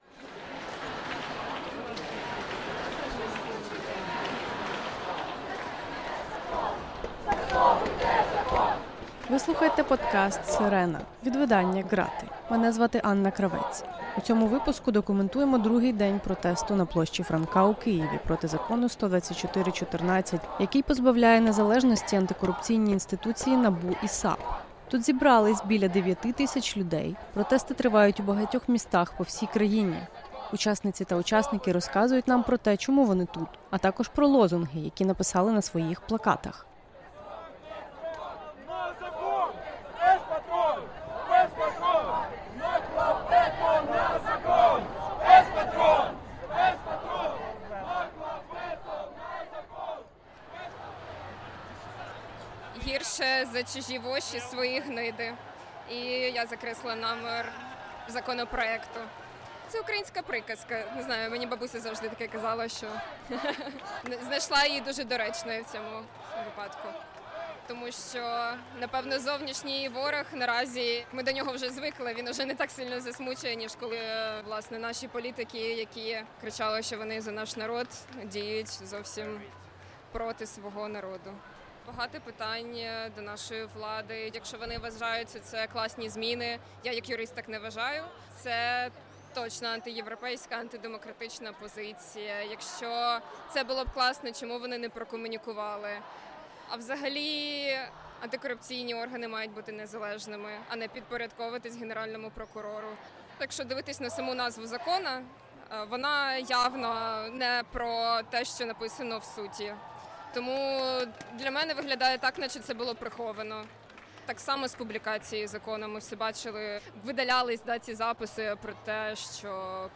Говоримо з учасницями та учасниками першого масового протесту під час великої війни.